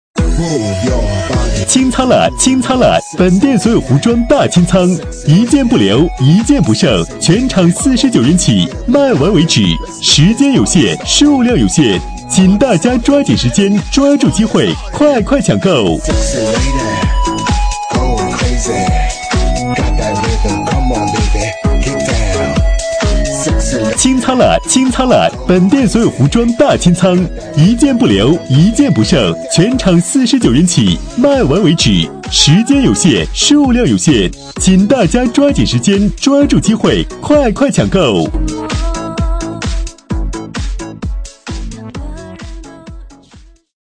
【男31号促销】大清仓
【男31号促销】大清仓.mp3